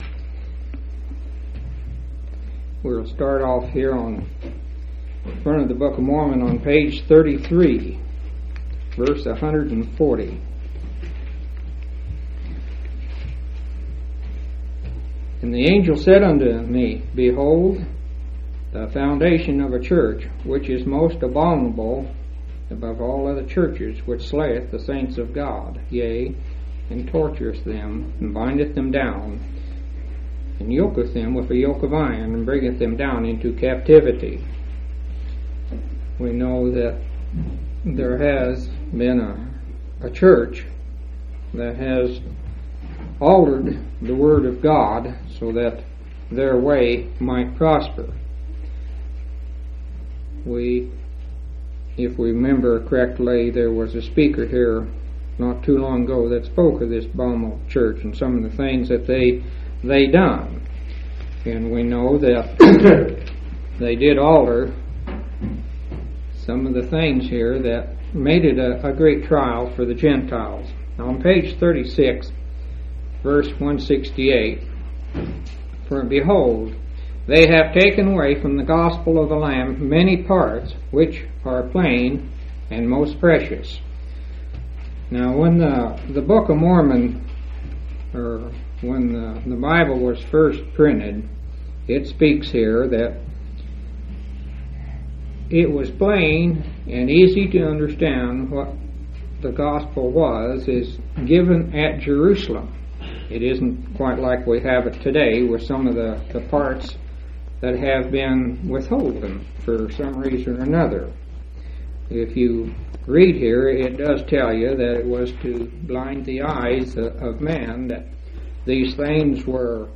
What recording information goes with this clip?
10/18/1987 Location: Grand Junction Local Event